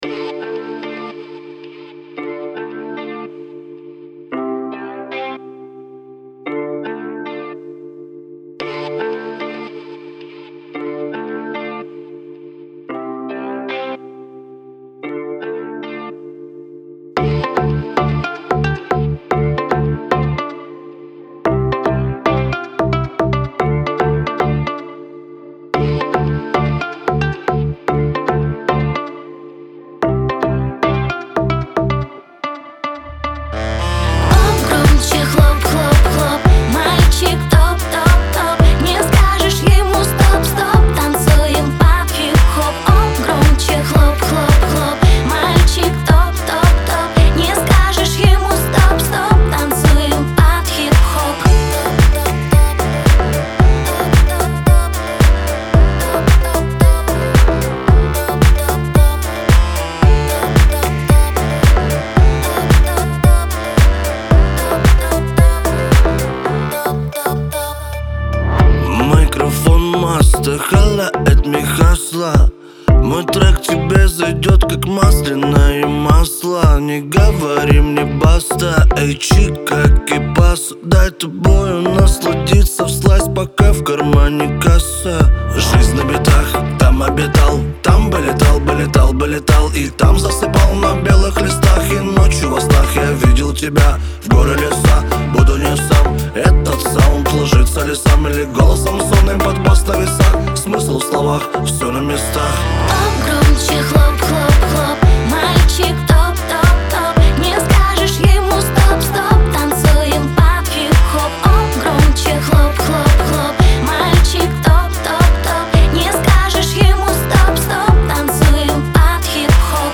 это запоминающаяся композиция в жанре поп-рок